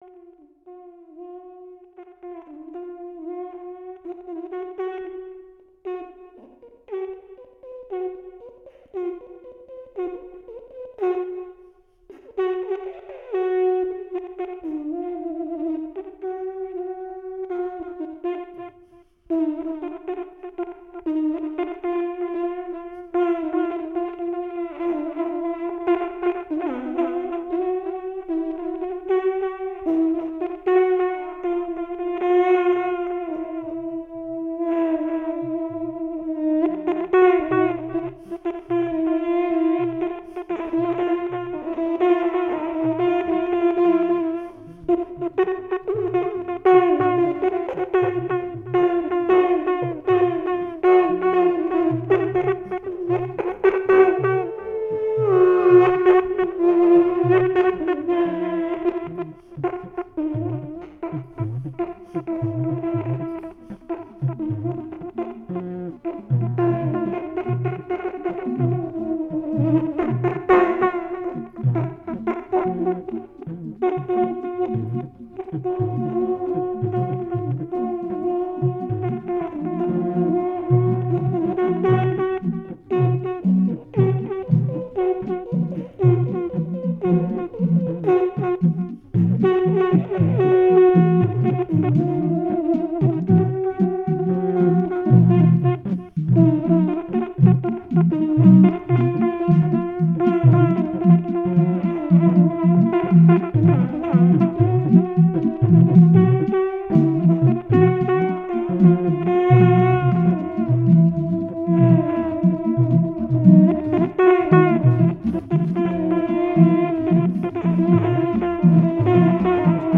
A concert for voices, instruments and images